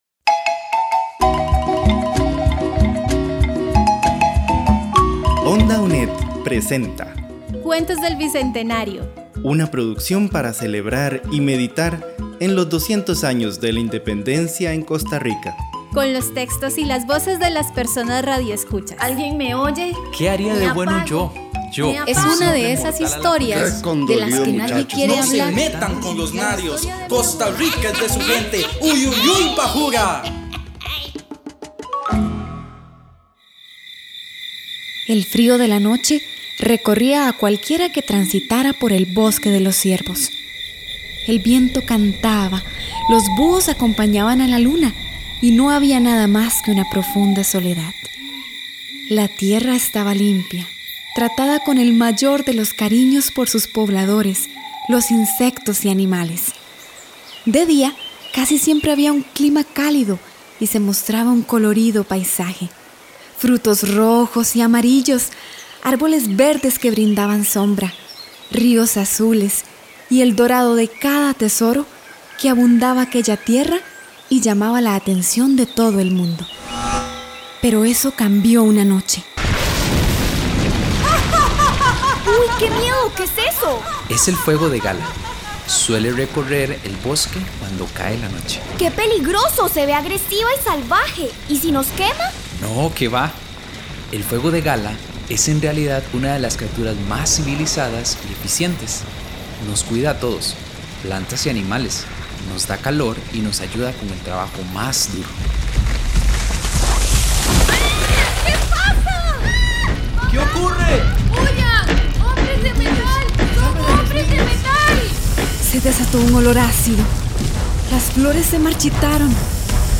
Adaptación radiofónica del cuento "Ardiendo en libertad", de la autora Débora Marchena Herrera.
Con las voces voluntarias